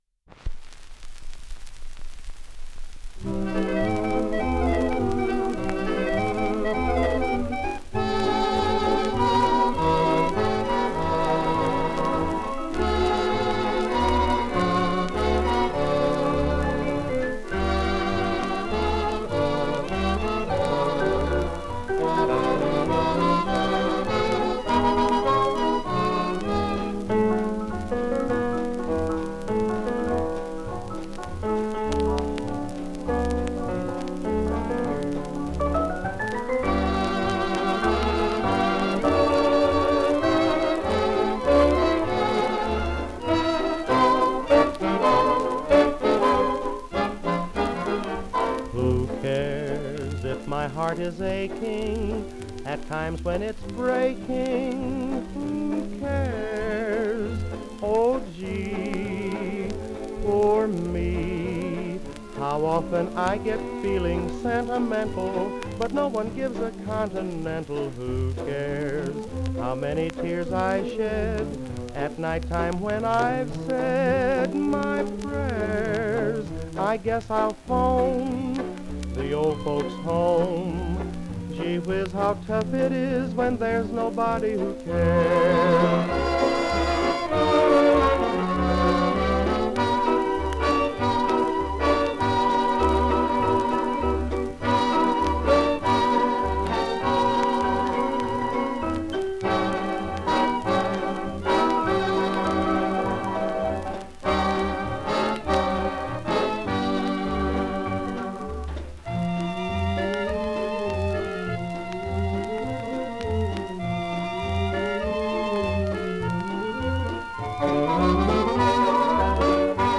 Genre: Popular Music.